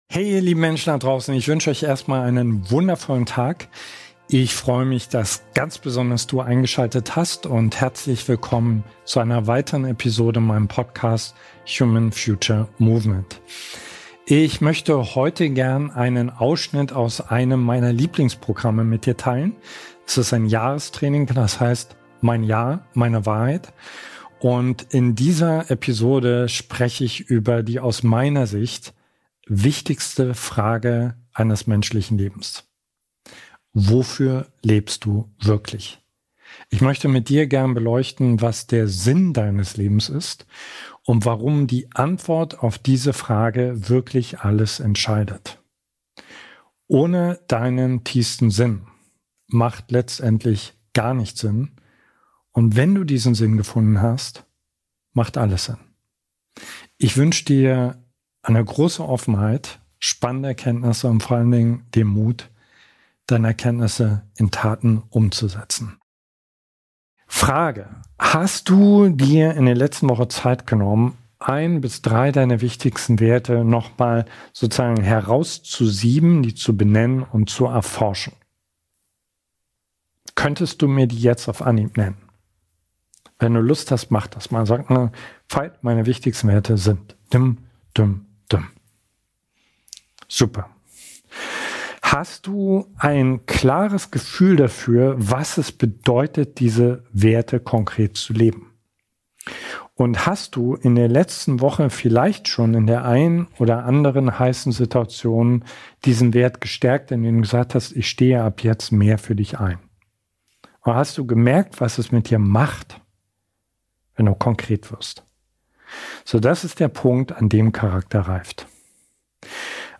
Hey, ihr lieben Menschen da draußen. Ich freue mich sehr, dass du hier bist – und ich möchte heute einen exklusiven Ausschnitt aus unserem Jahrestraining Mein Jahr. Meine Wahrheit. mit dir teilen.